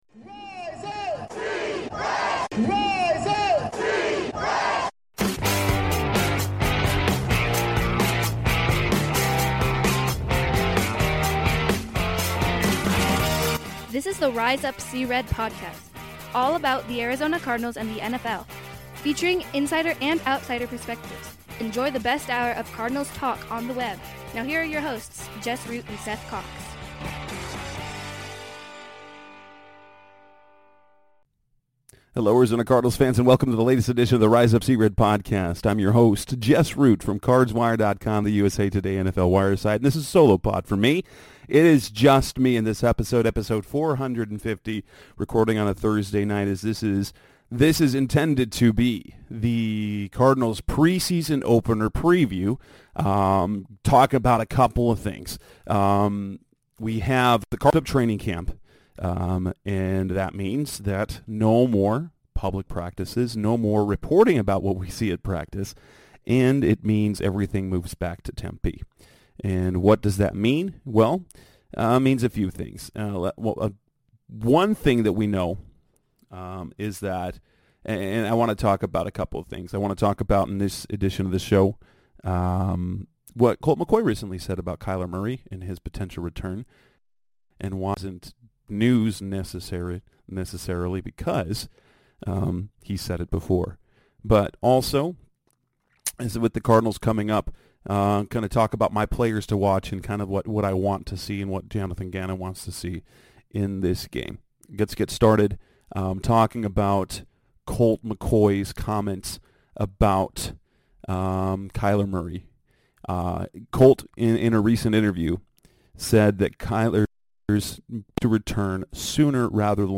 a solo show